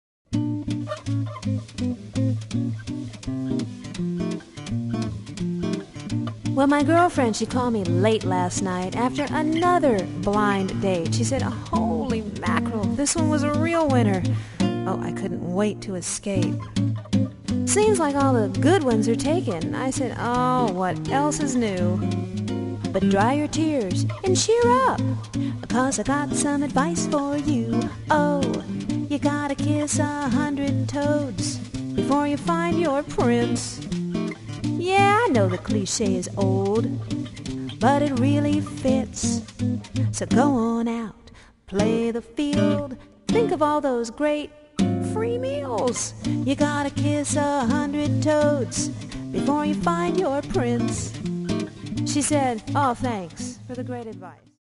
--humorous acoustic music